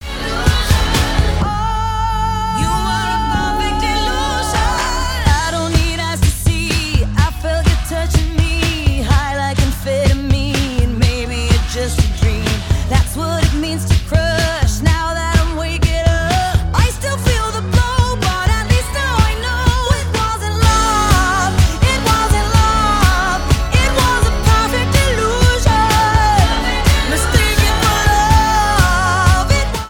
• Pop
A pop, dance-rock and pop rock song